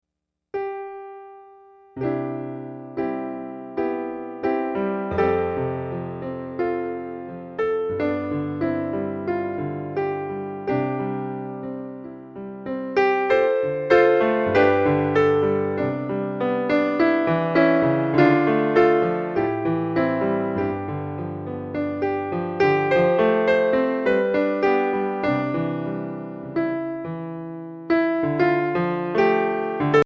Instrumental Album Download